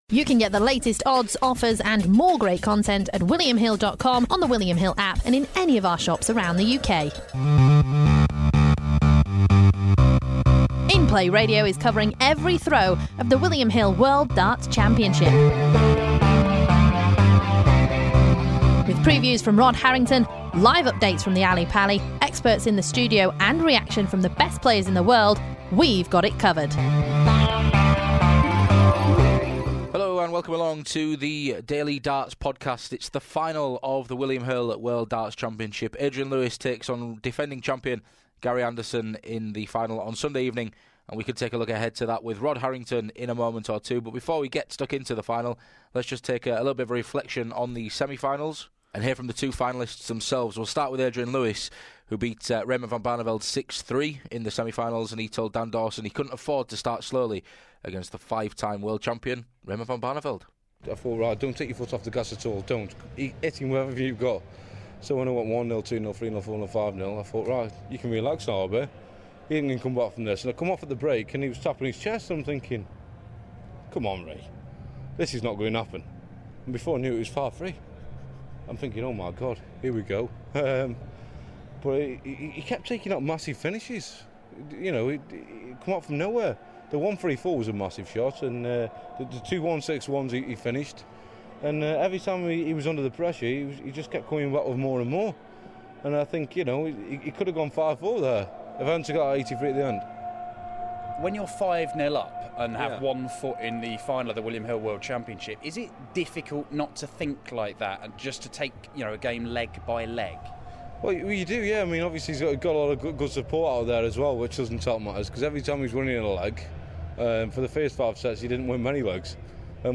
We hear from both players on this edition before Rod Harrington joins us to offer his bets for the final.